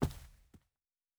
Footstep Carpet Walking 1_01.wav